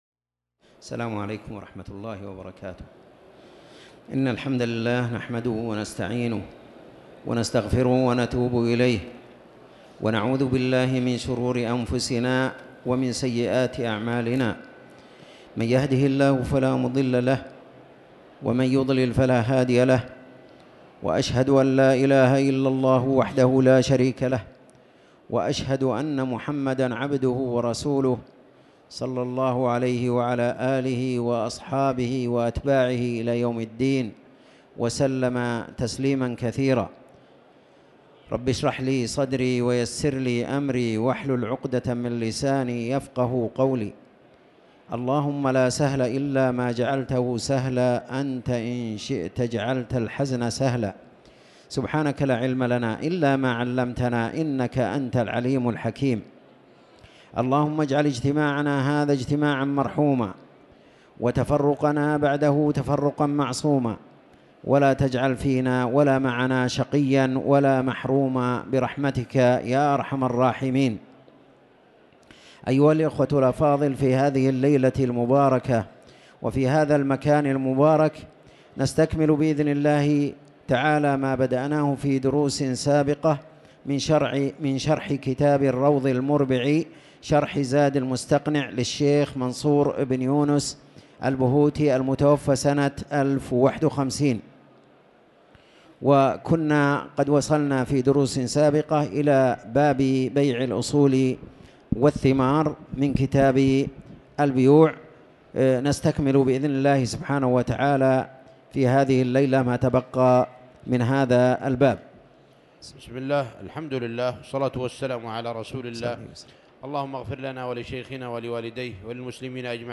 تاريخ النشر ٢٨ جمادى الآخرة ١٤٤٠ هـ المكان: المسجد الحرام الشيخ